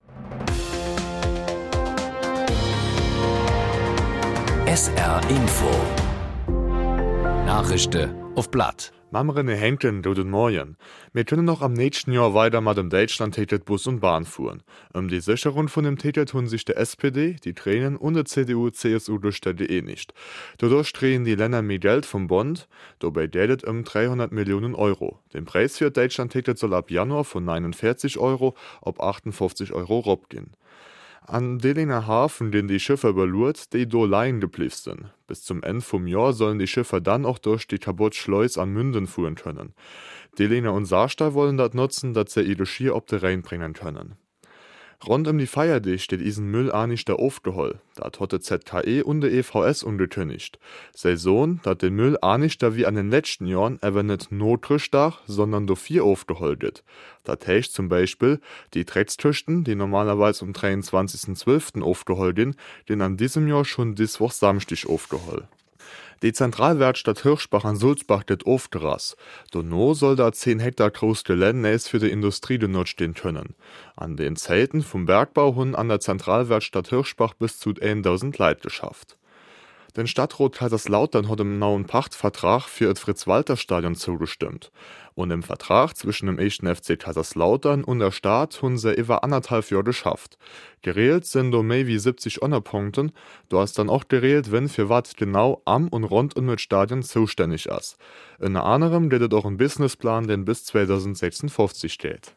Nachrichten
Die "Nachrischde uff platt" werden mal in moselfränkischer, mal in rheinfränkischer Mundart präsentiert. Von Rappweiler bis Dudweiler, von Dillingen bis Püttlingen setzt sich das Team aus Sprechern ganz verschiedener Mundartfärbungen zusammen